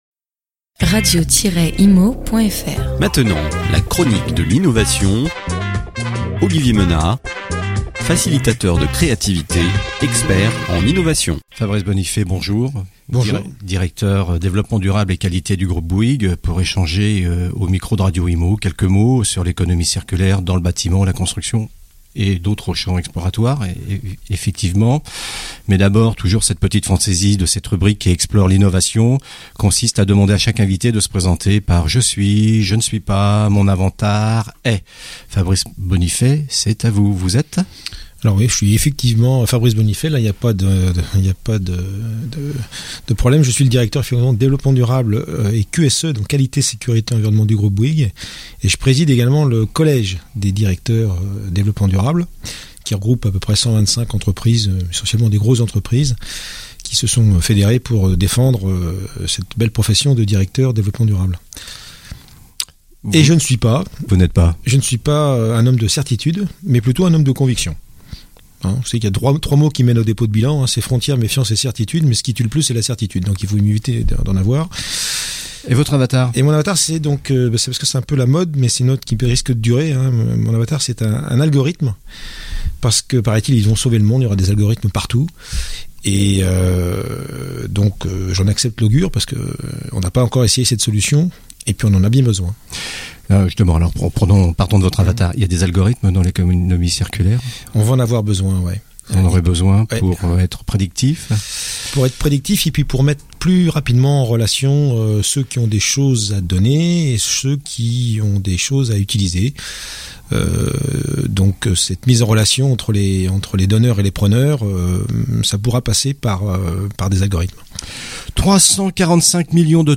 Economie circulaire : interview radio